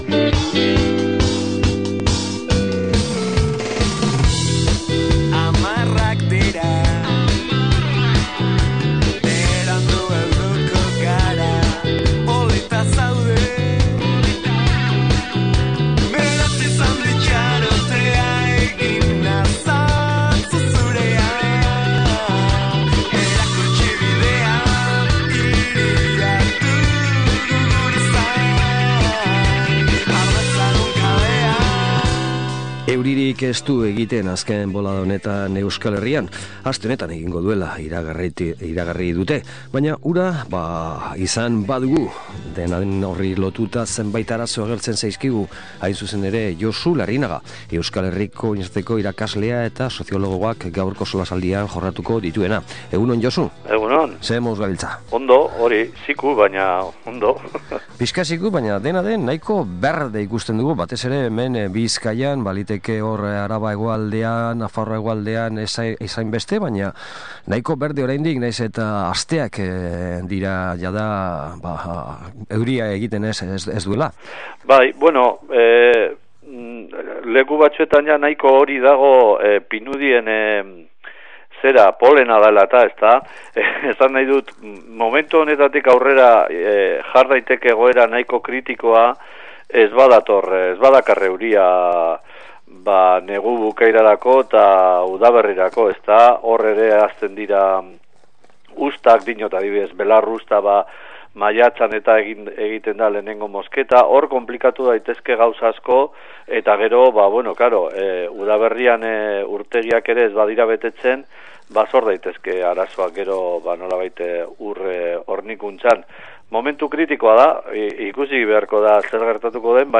SOLASALDIA: CO2, Ur Partzuergoa, Itoitz…